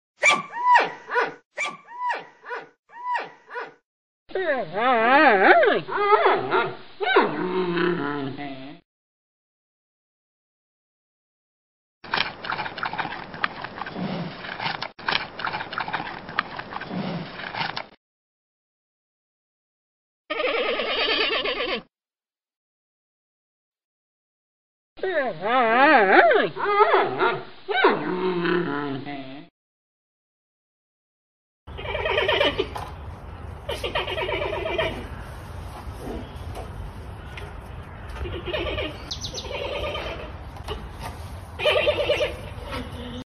熊猫叫声纯享版.MP3